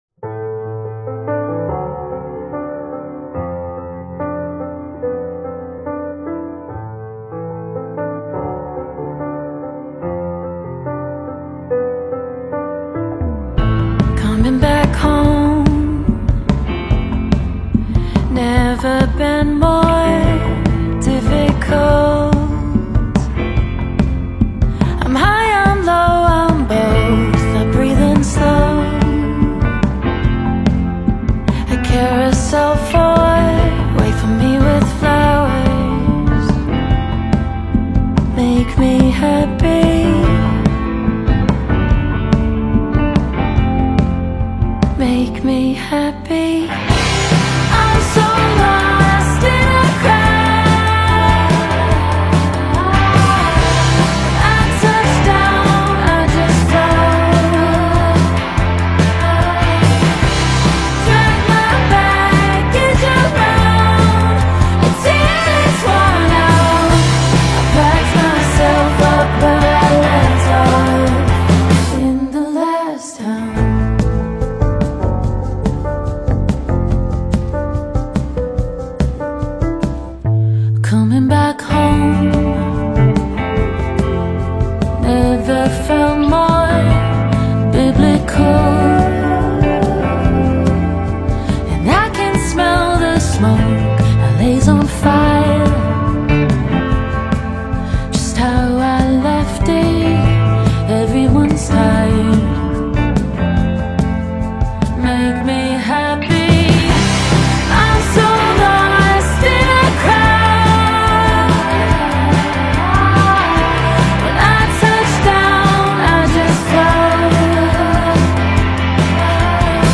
feels cinematic without trying to announce itself as such